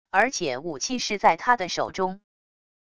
而且武器是在他的手中wav音频生成系统WAV Audio Player